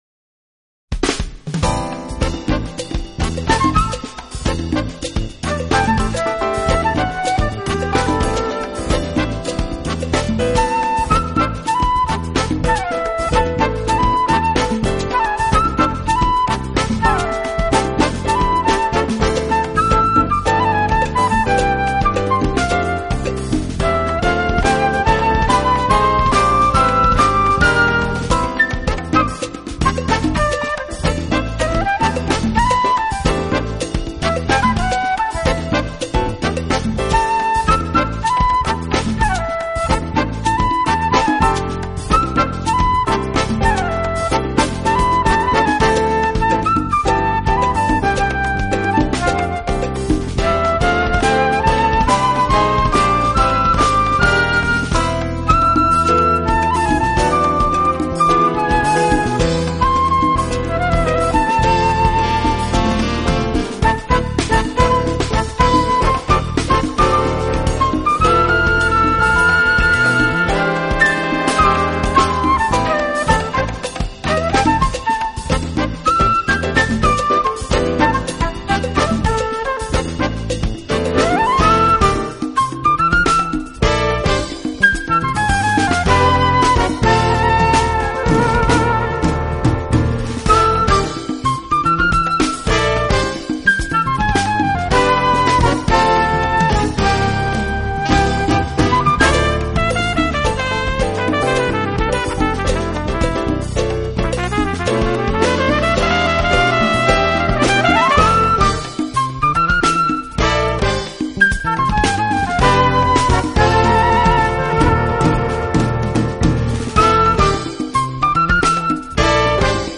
【爵士长笛】